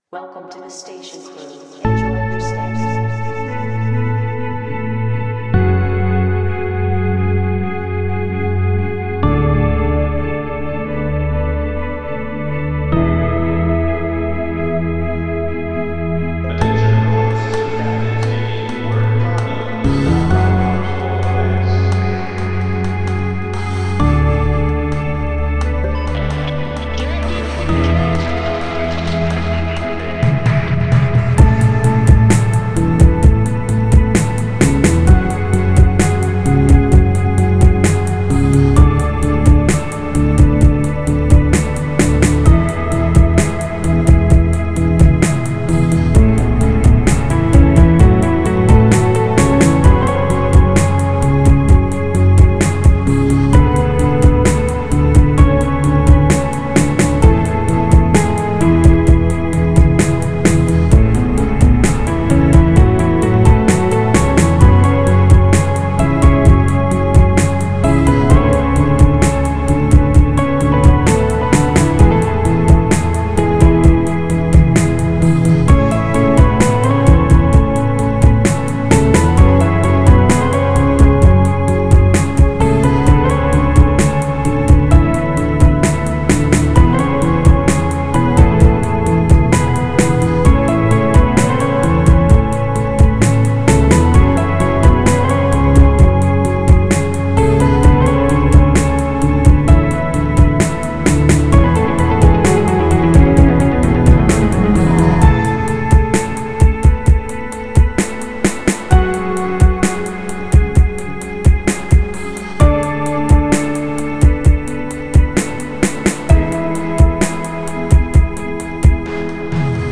funky song